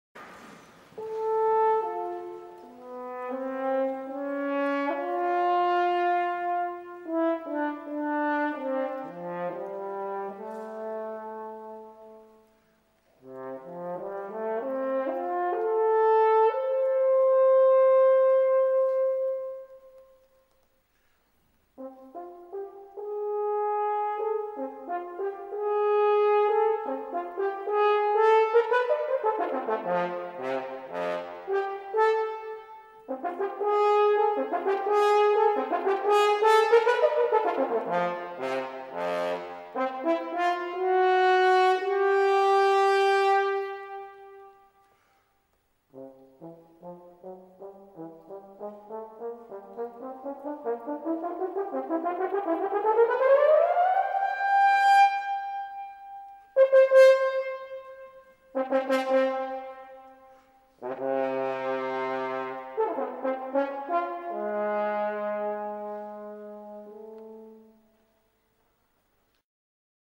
TROMPA (viento metal)